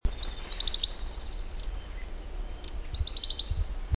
15-6麟趾山口2012march28栗背林鴝msong2.mp3
栗背林鴝 Tarsiger johnstoniae
南投縣 信義鄉 玉山麟趾山口
錄音環境 灌木叢
雄鳥鳴唱聲